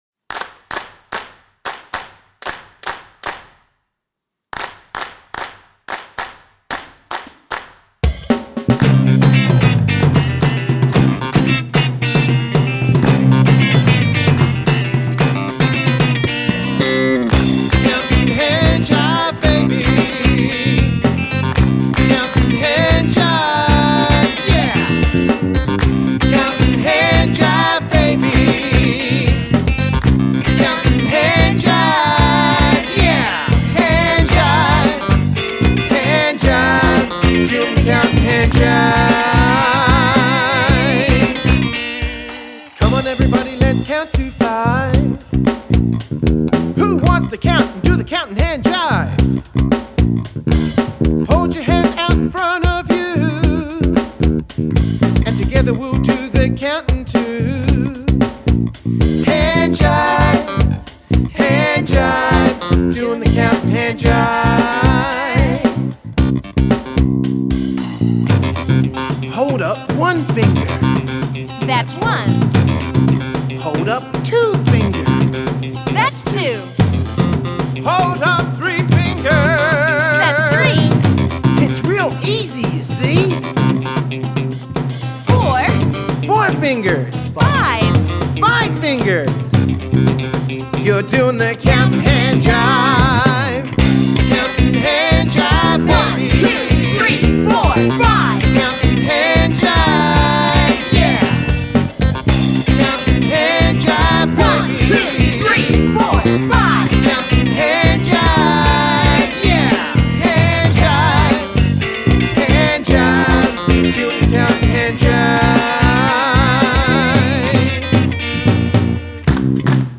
Genre: Funky Disco/Soul